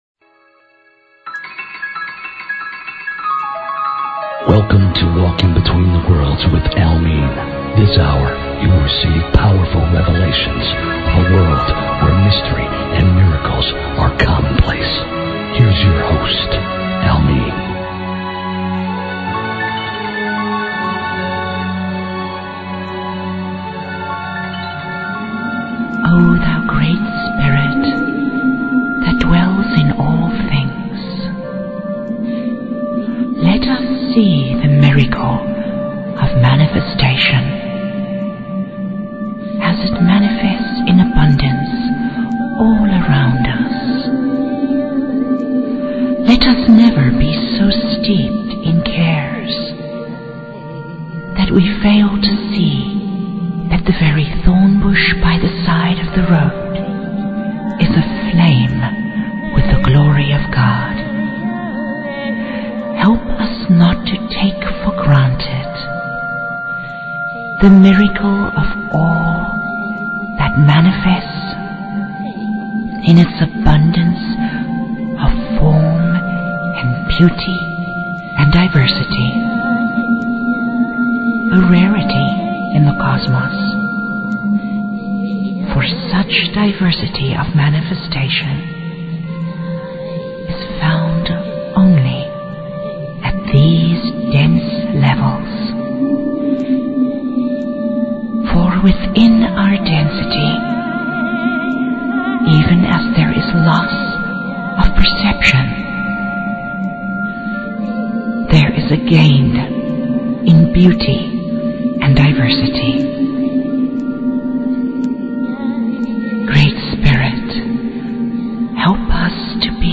Talk Show Episode, Audio Podcast, Secrets_of_the_Hidden_Realms and Courtesy of BBS Radio on , show guests , about , categorized as
These interviews are powerful enough to change your life!